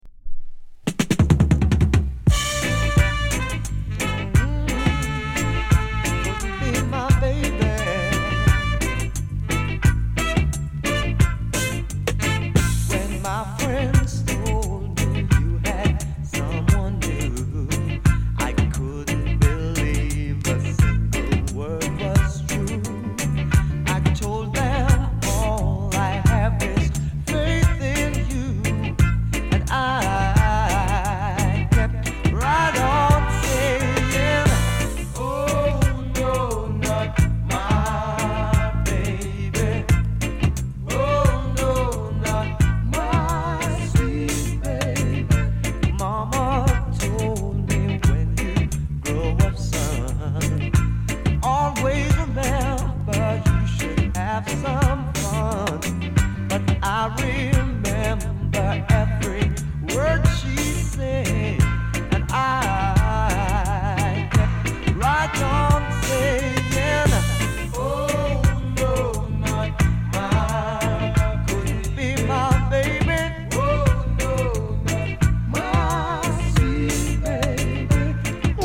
US 高音質